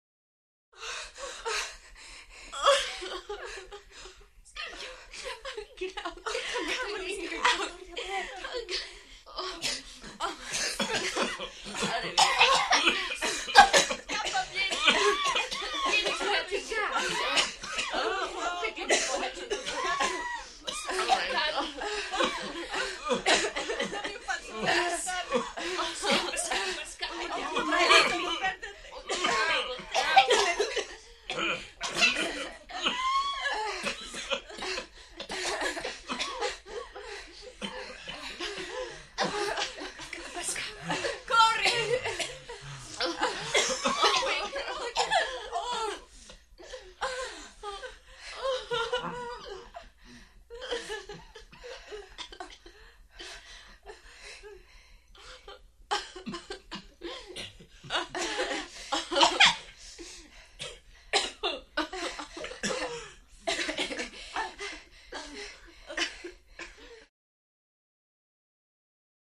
Interior Small Group Of Italian Women Sobbing, Cries And Screams.